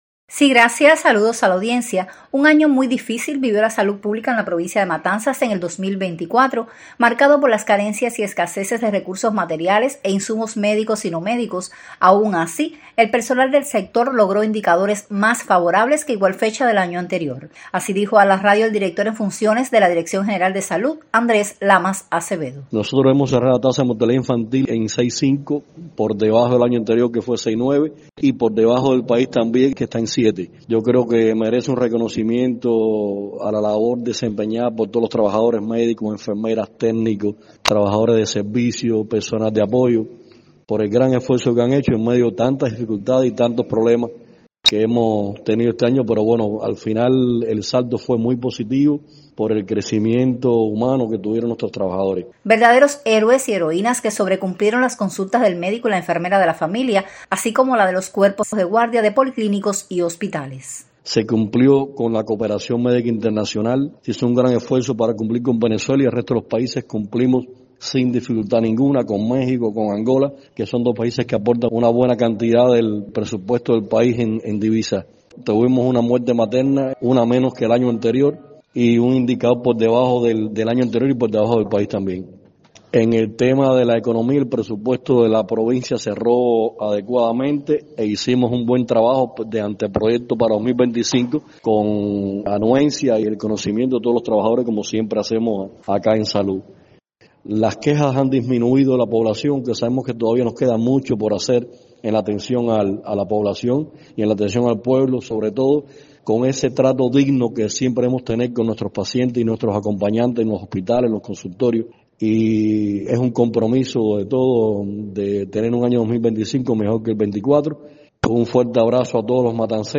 Así dijo a la radio el director en funciones de la Dirección General de Salud, Andrés Lamas Acevedo.